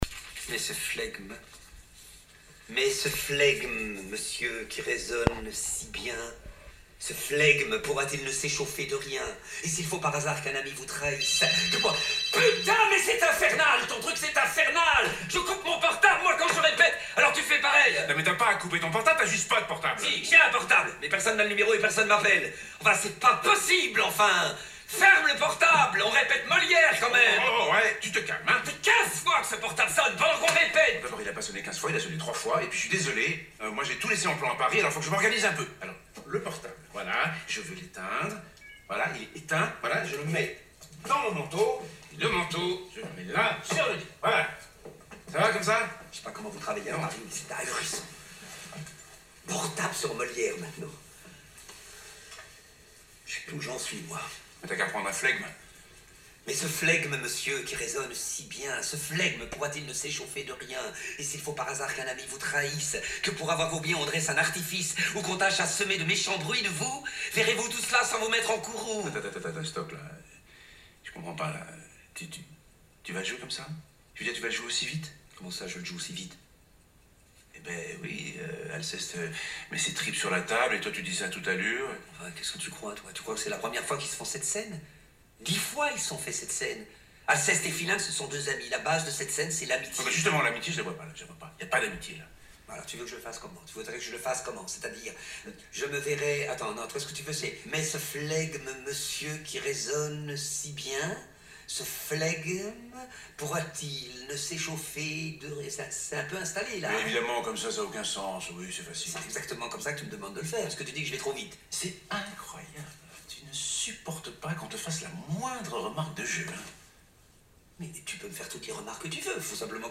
6. Enfin c’est pas possible ! : cette exclamation, dite sur ce ton, exprime l’énervement.
10. Attends… : On prononce le premier Attends correctement, mais ensuite, on ne dit plus que quelque chose comme ‘tends ‘tends ‘tends dans ce genre d’exclamation.
Serge (Fabrice Luchini) fait une scène à Gauthier (Lambert Wilson) à cause du portable de ce dernier.